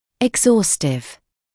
[ɪg’zɔːstɪv][иг’зоːстив]исчерпывающий, всесторонний, обстоятельный; истощающий